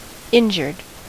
Ääntäminen
Ääntäminen US Tuntematon aksentti: IPA : /ɪndʒə(ɹ)d/ Haettu sana löytyi näillä lähdekielillä: englanti Käännös Adjektiivit 1. verletzt 2. wund Substantiivit 3.